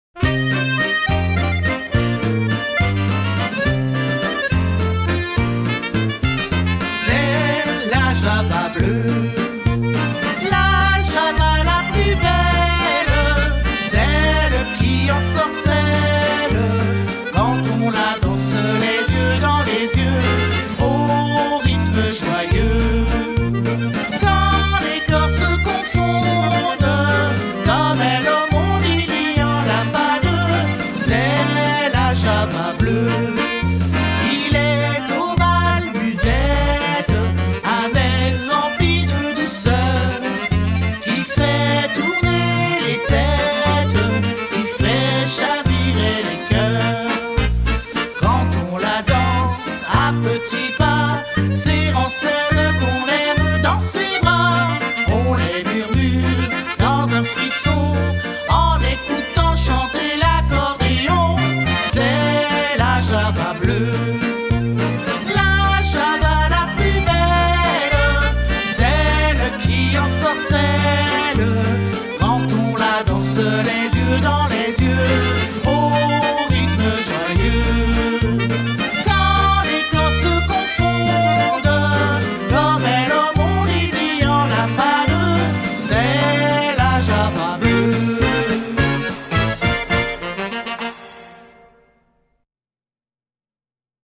La version chantée